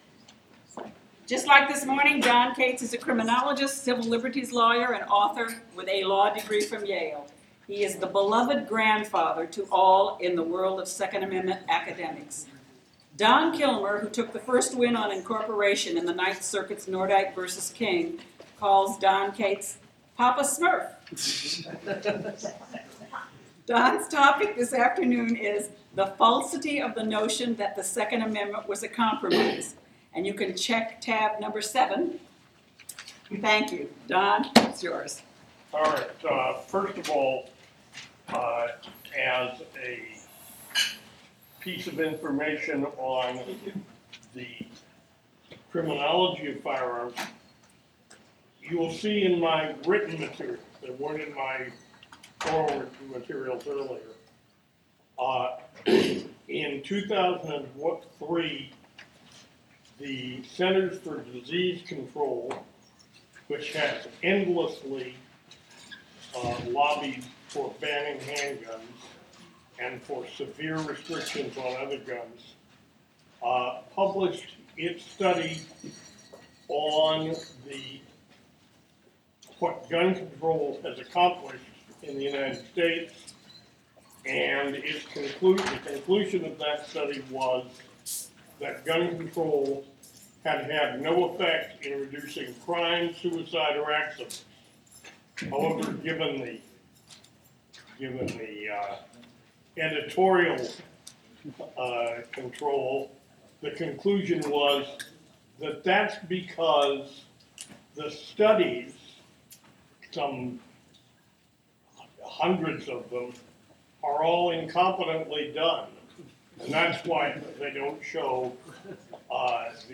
NRA Civil Rights Defense Fund Next Generation Scholars Seminar